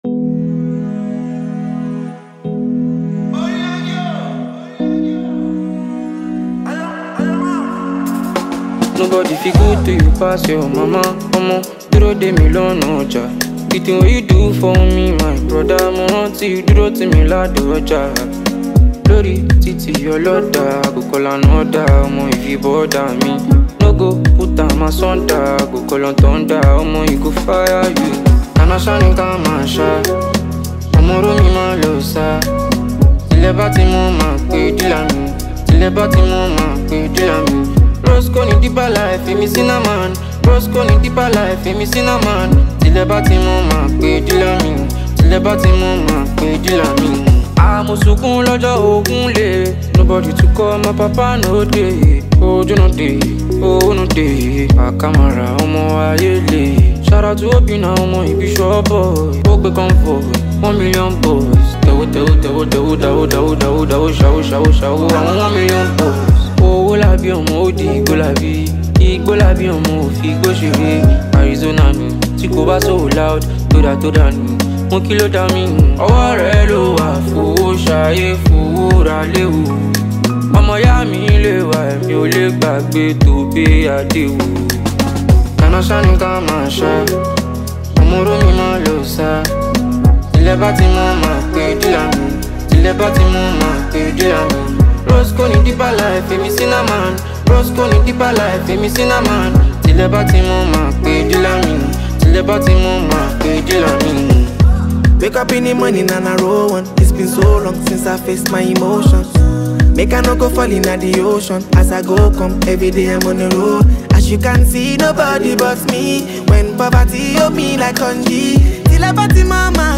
a fresh vibe
resulting in a polished and dynamic sound.